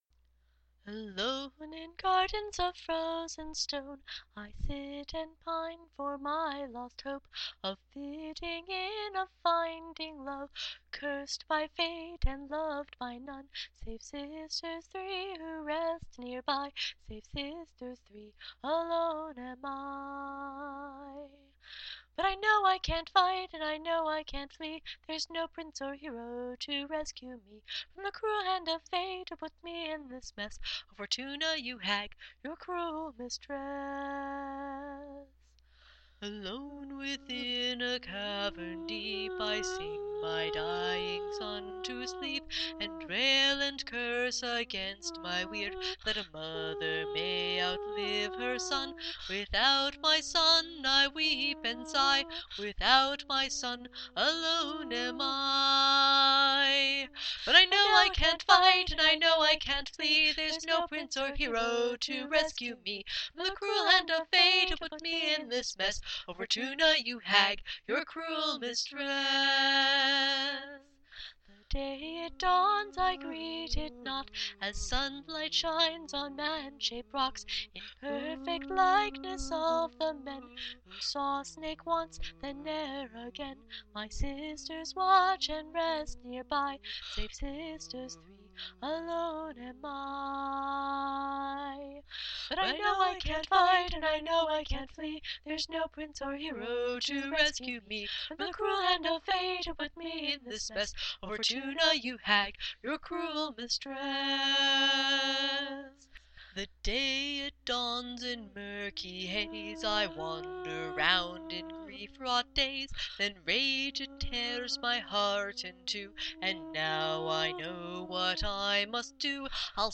It features a duet between two villains who seem to be victims of their own circumstances — Medusa and Grendel’s Mother.